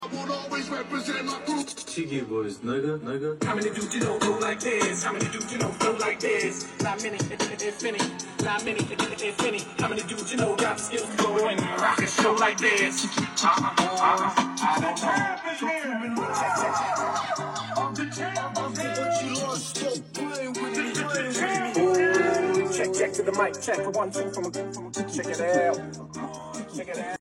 This was a competition for who has the loudest car midbox speakers in the whole of NZ.
Each play a song for 1 minute. The winner is determined on clearity and loudness.